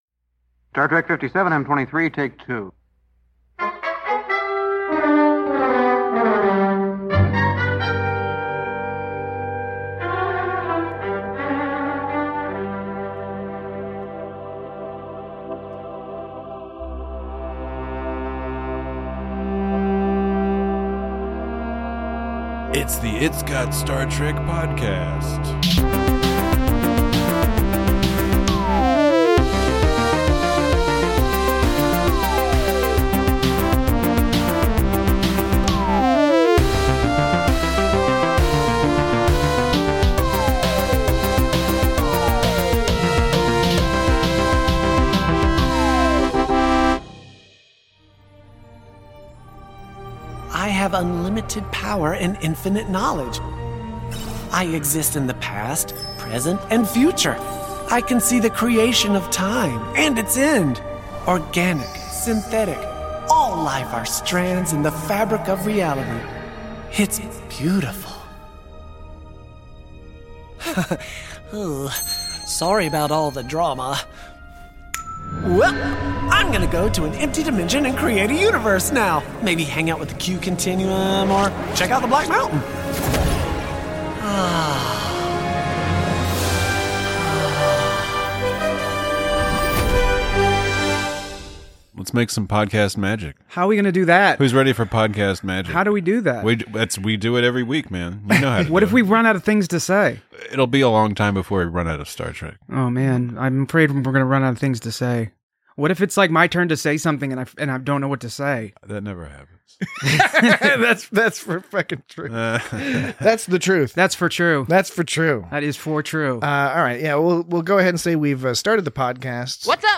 Tendi, Rutherford, and Boimler help wrap up some loose AI ends. Join your fully human hosts as they discuss digital apotheosis, excellent voice acting, and the infectious joyfulness of Tendi building sand castles in Ecuador.